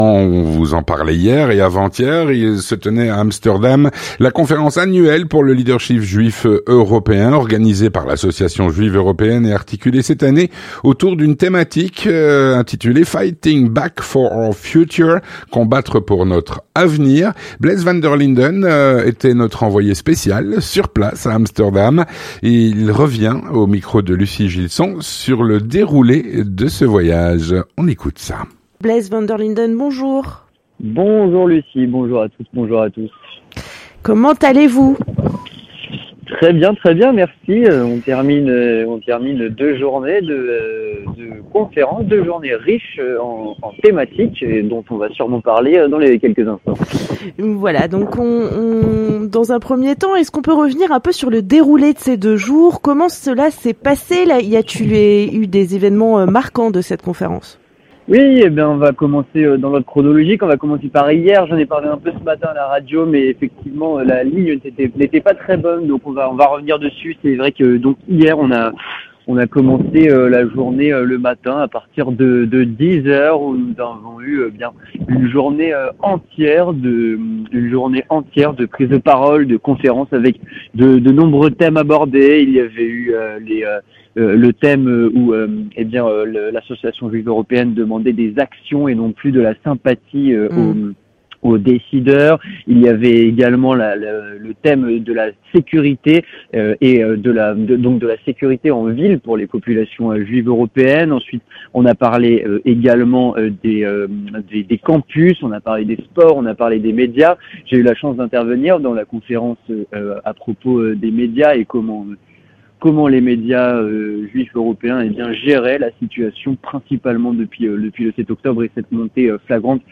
L'entretien du 18H - Retour sur la Conférence annuelle pour le leadership juif européen à Amsterdam.